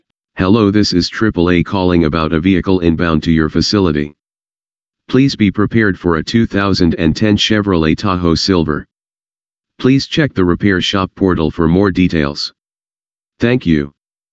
The system will email, text and call your shop’s main phone line and inform you that a member is inbound to your facility.
Shop Phone Tow Notification
Sample Notification.wav